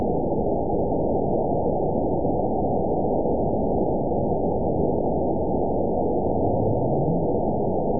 event 919868 date 01/26/24 time 16:46:04 GMT (1 year, 3 months ago) score 9.39 location TSS-AB03 detected by nrw target species NRW annotations +NRW Spectrogram: Frequency (kHz) vs. Time (s) audio not available .wav